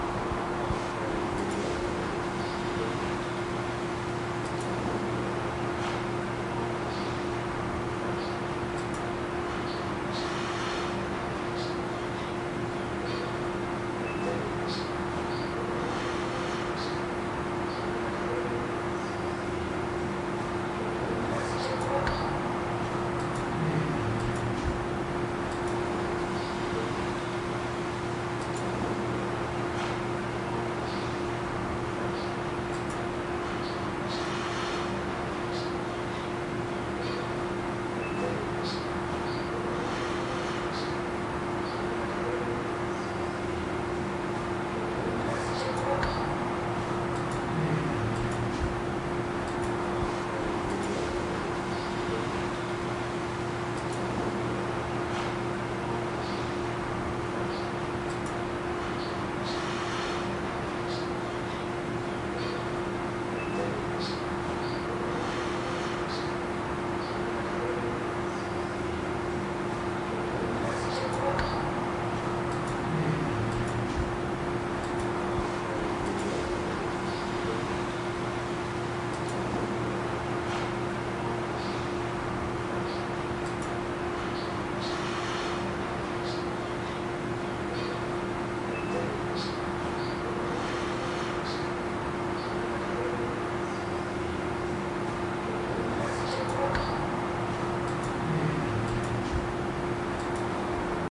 办公室氛围 01 OWI
描述：使用步枪麦克风录制。安静的办公室氛围
Tag: 办公室 安静 OWI 氛围